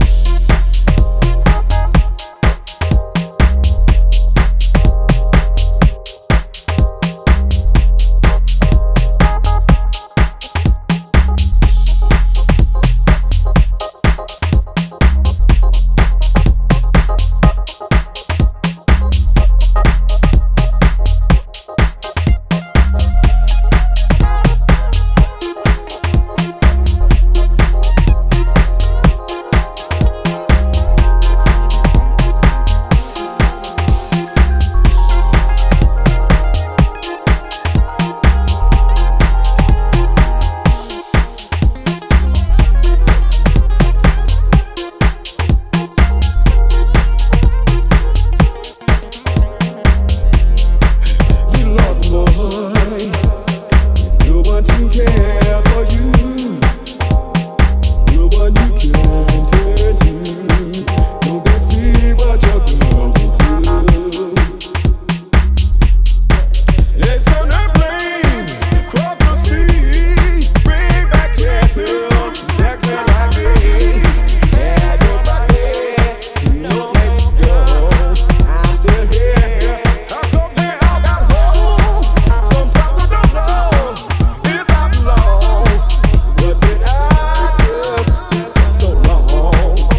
ジャンル(スタイル) DEEP HOUSE / SOULFUL HOUSE